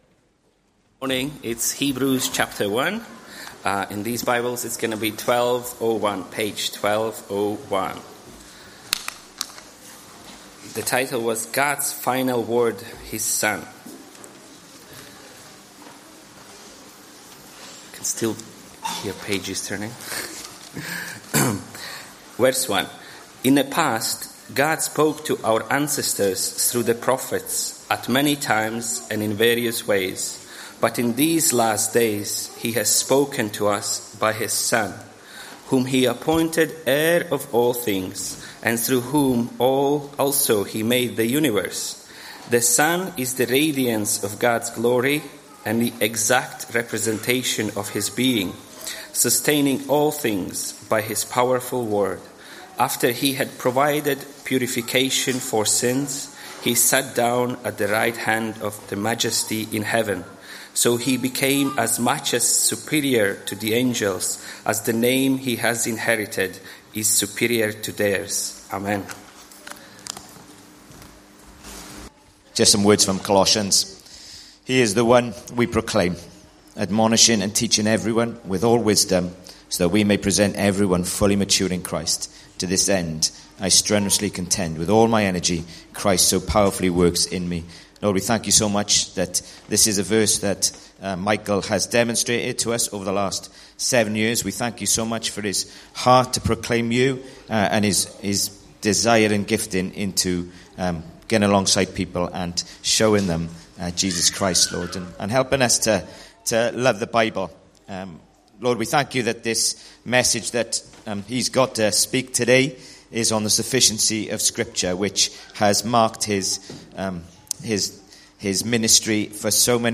Hebrews 1:1-4; 28 July 2024, Morning Service.